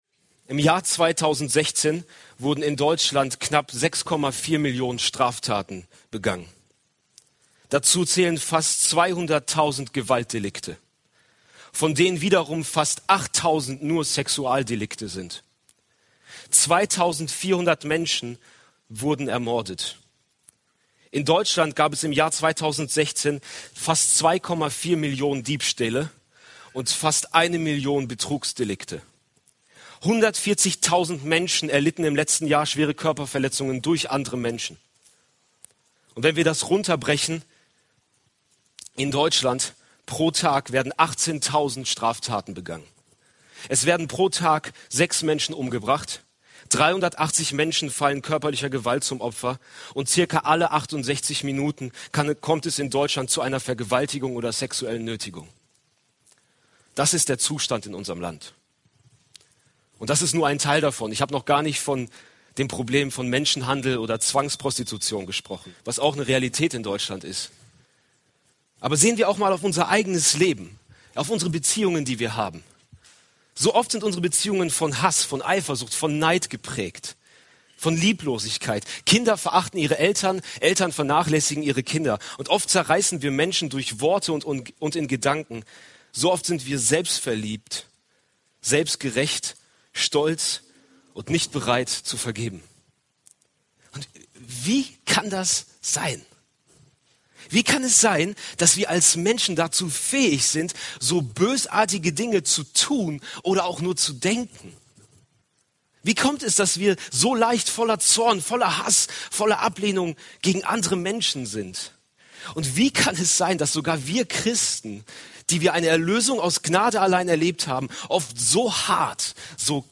Predigt-Aufnahmen Josia-Konferenz 2017 | Josia – Truth for Youth